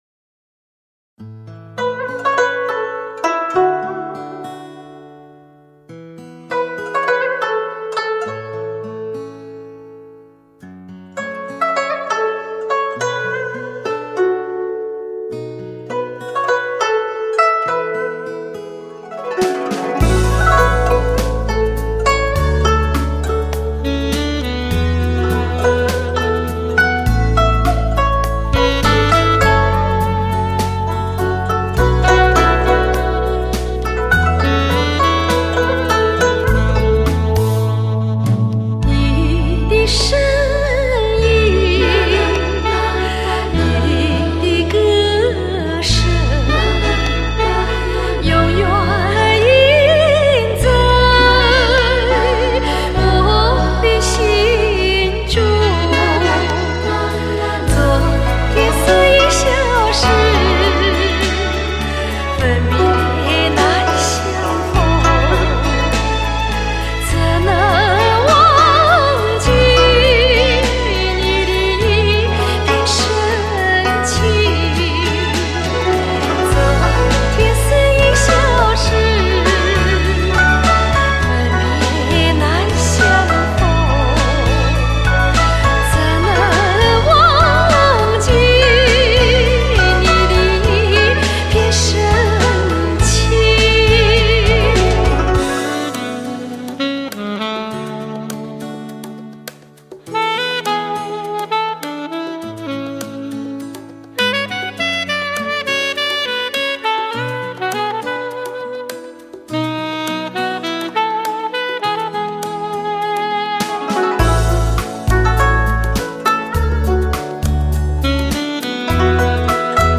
按发烧级要求重新编配、真乐器伴奏